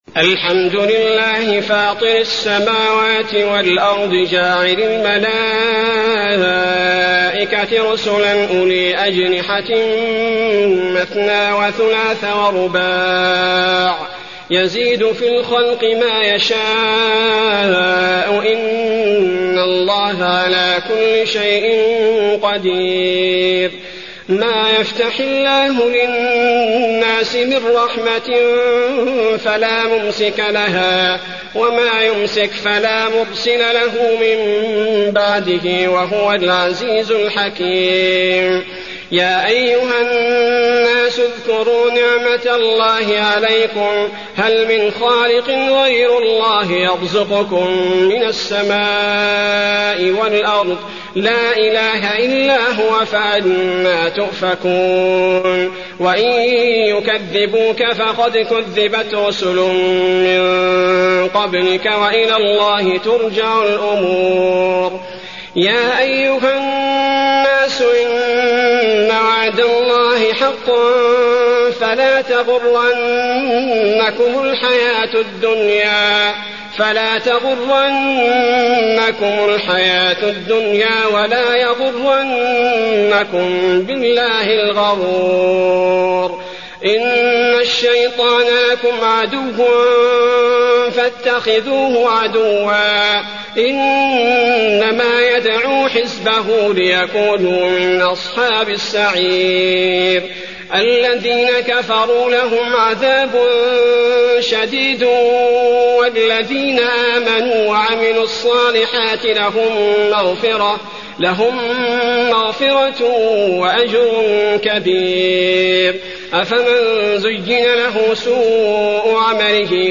المكان: المسجد النبوي فاطر